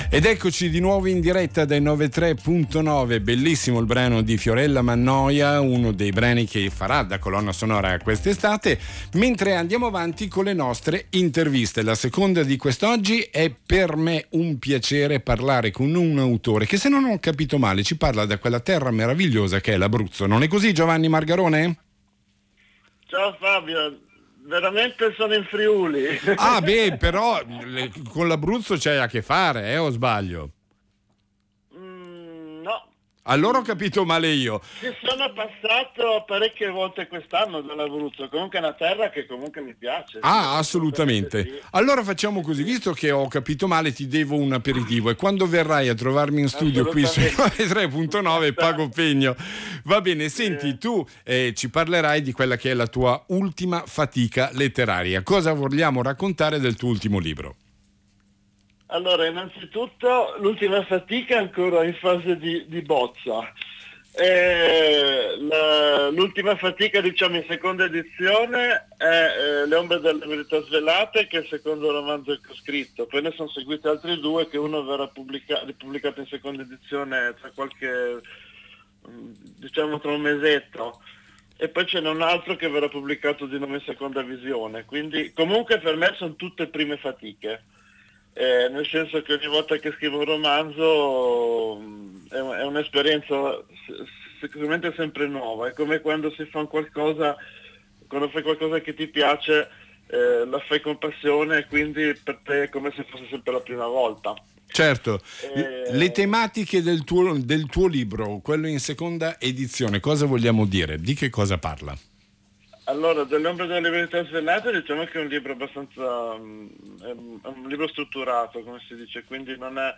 RASSEGNA STAMPA: audio dell’intervista a Radio Cernusco Stereo
CLICCANDO QUI SOPRA POTRETE ASCOLTARE L’INTERVISTA DEL 15 GIUGNO 2019 A RADIO CERNUSCO STEREO.